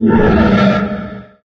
255081e1ee Divergent / mods / Soundscape Overhaul / gamedata / sounds / monsters / poltergeist / idle_3.ogg 26 KiB (Stored with Git LFS) Raw History
idle_3.ogg